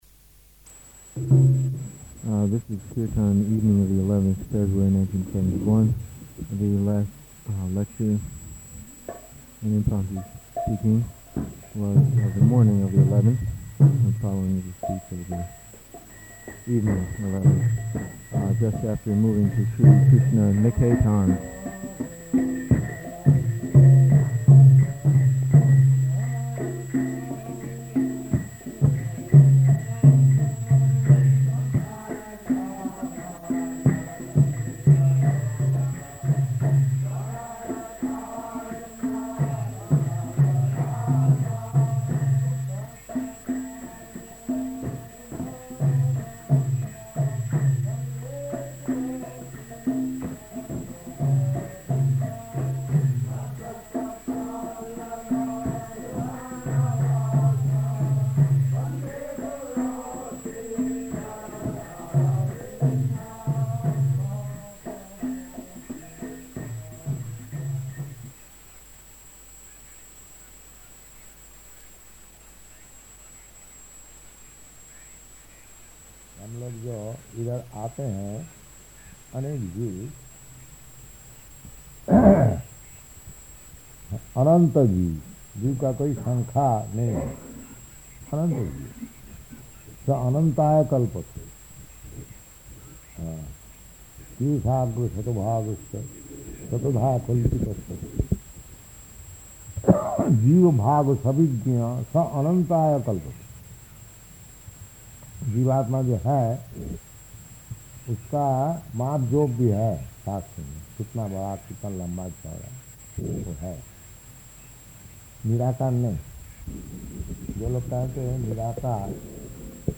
Lecture in Hindi
Type: Lectures and Addresses
Location: Gorakphur